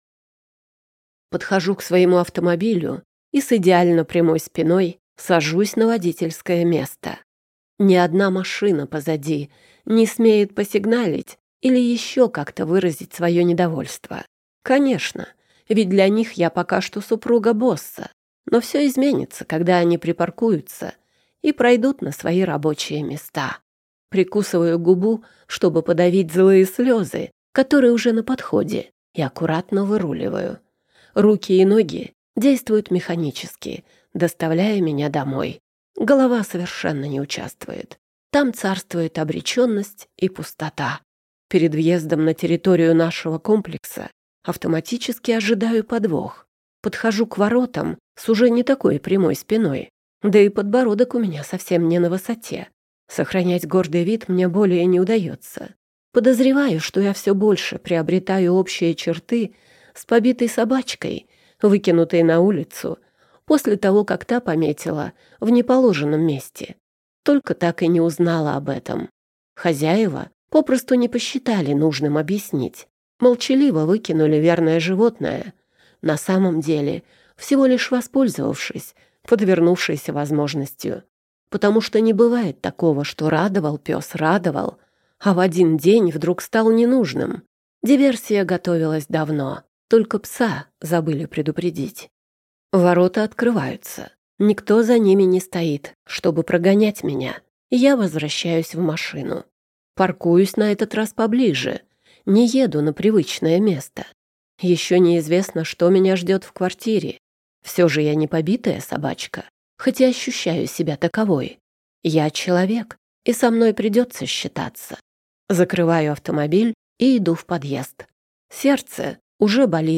Аудиокнига Предатель. Ты не узнаешь о двойне | Библиотека аудиокниг